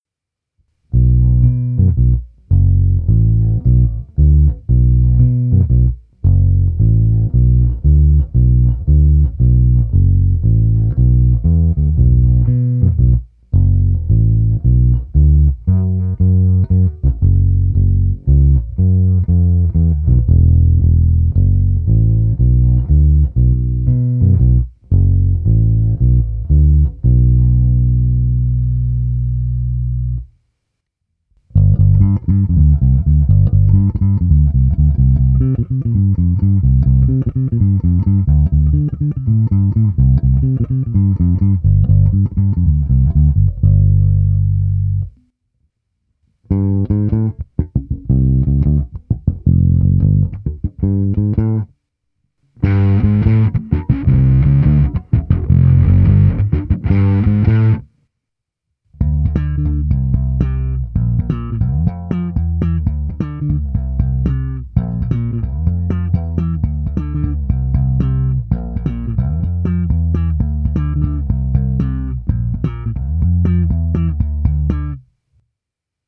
Následující ukázky byly pořízeny s nylonovými hlazenými strunami D'Addario ETB92 Tapewound Bass s tloušťkami .050" až .105."
Nahrávka přes preamp Darkglass Alpha Omega Ultra se zapnutou simulací aparátu a také přes kompresor TC Electronic SpectraComp. Nahrávka je v pořadí krkový snímač, oba snímače, kobylkový snímač bez a se zkreslením a slap na oba snímače.